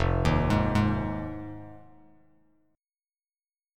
Fm7 Chord
Listen to Fm7 strummed